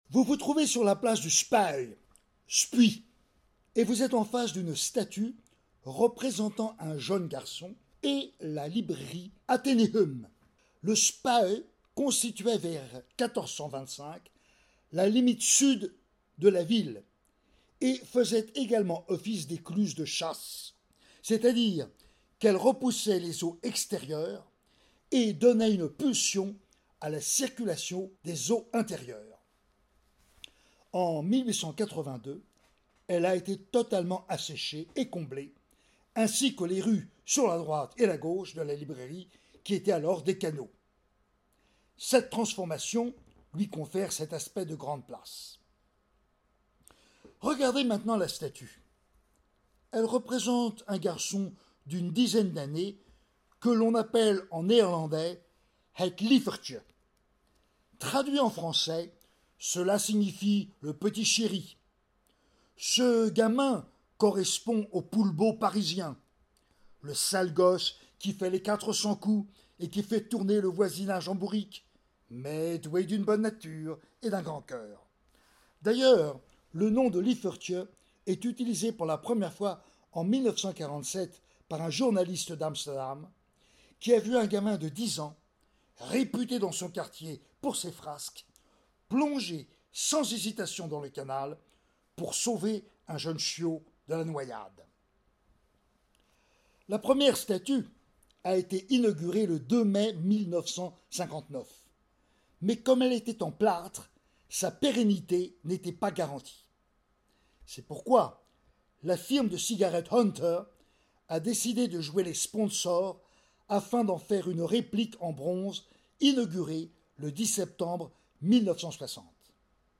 Vous voici désormais devant la statue d’un jeune garçon, laisser le guide vous raconter l’histoire de ce lieu ainsi que de la statue.